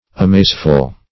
Amazeful \A*maze"ful\, a. Full of amazement.
amazeful.mp3